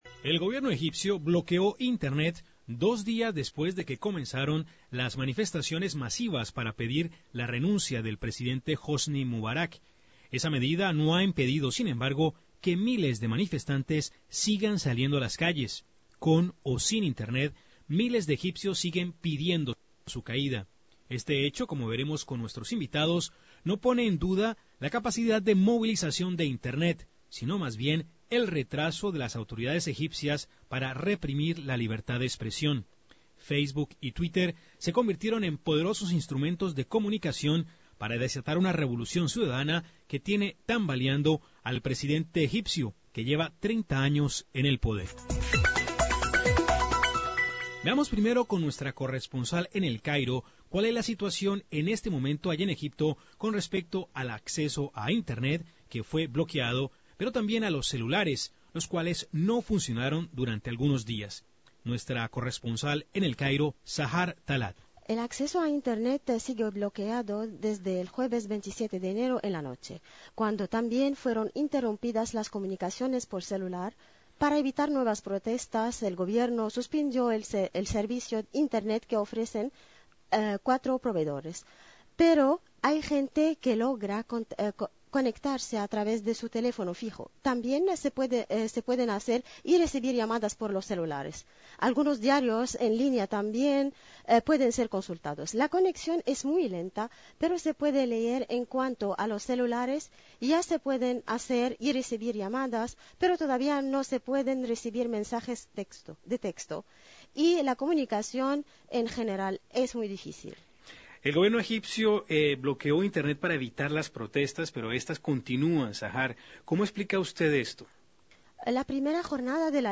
A pesar del bloqueo a Internet luego del comienzo de las manifestaciones, las redes sociales juegan un papel preponderante. Escuche el informe de Radio Francia Internacional.